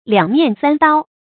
注音：ㄌㄧㄤˇ ㄇㄧㄢˋ ㄙㄢ ㄉㄠ
兩面三刀的讀法